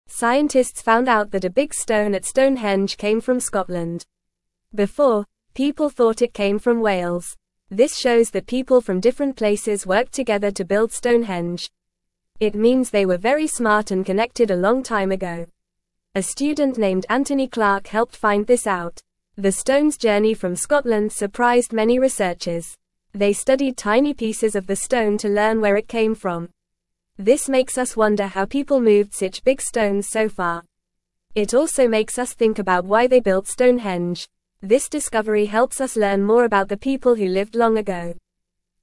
Fast
English-Newsroom-Beginner-FAST-Reading-Big-Stone-at-Stonehenge-Came-from-Scotland-Not-Wales.mp3